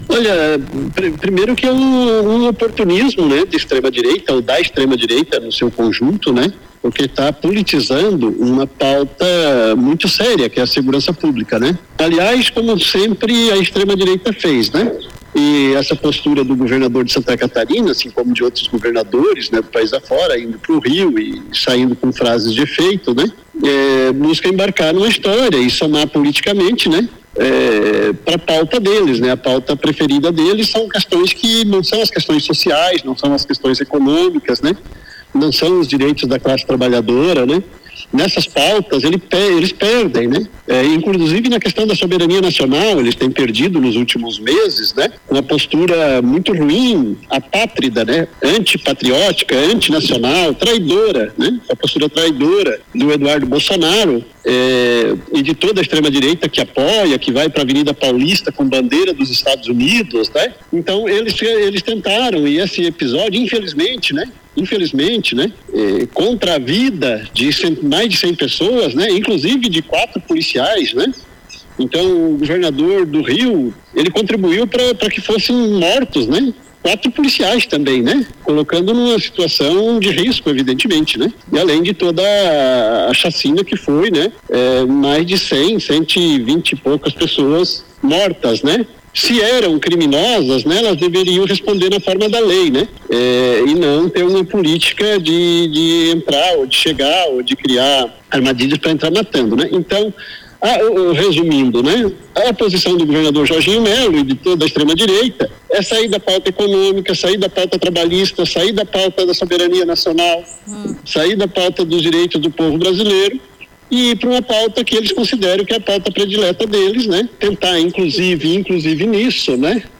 Entrevista com o ex-dirigente da Aprasc (Associação dos Praças) e ex-deputado Amauri Soares, que é aposentado da Polícia Militar. Ele fala sobre o massacre no Rio de Janeiro e sobre a postura do governador de Santa Catarina diante deste episódio. Segundo Amauri, a segurança pública segue sendo um tema muito pouco trabalhado pela esquerda brasileira.